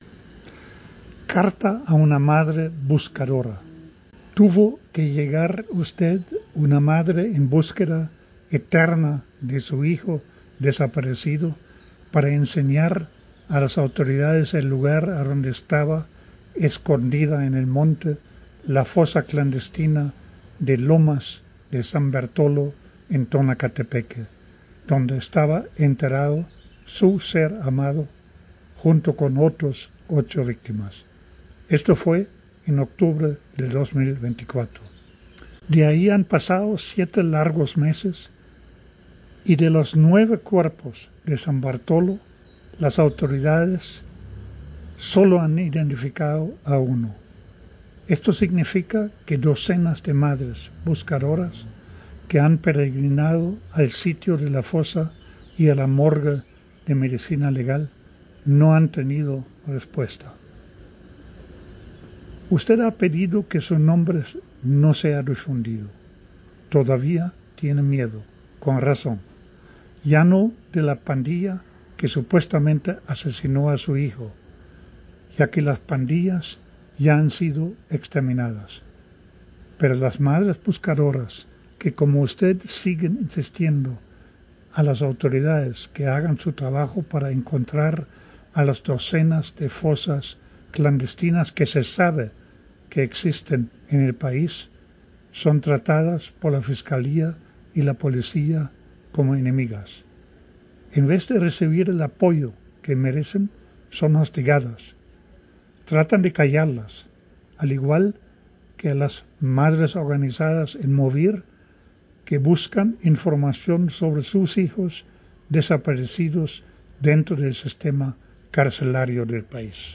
En la voz del autor: